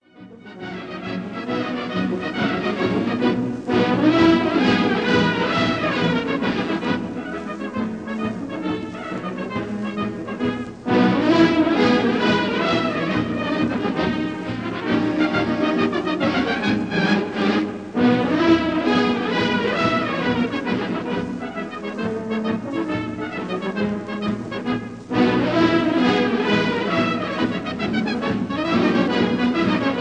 Recorded live in the Crystal Palace, London